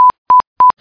BEEPWAVE.mp3